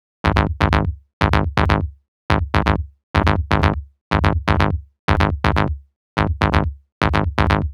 Bleep Hop Bass.wav